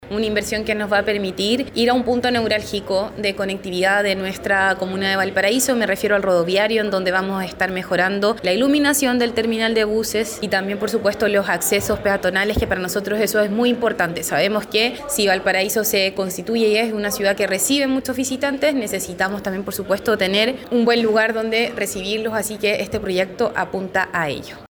La alcaldesa porteña, Camila Nieto, apuntó a la importancia de estos trabajos, esto al ser un punto clave para la conectividad de Valparaíso y el ingreso de turistas.